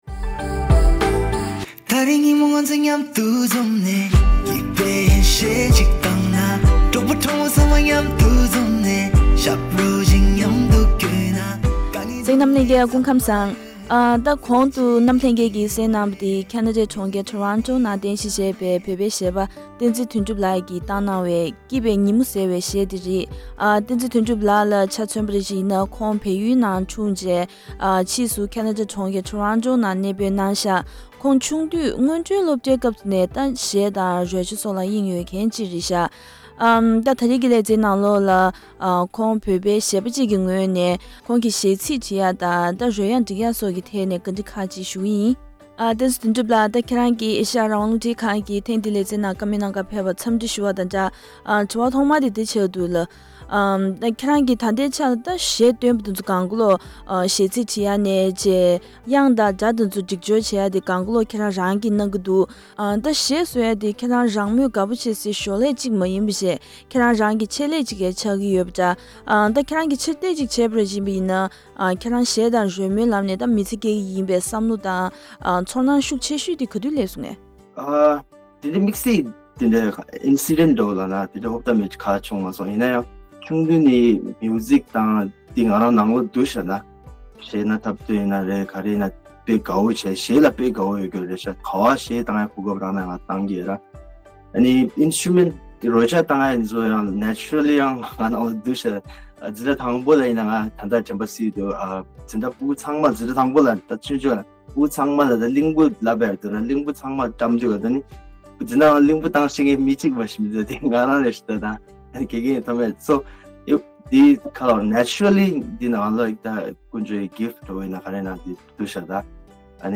བཀའ་འདྲི་ཞུས་པའི་ལེ་ཚན་འདི་གཤམ་ལ་གསན་གནང་གི་རེད།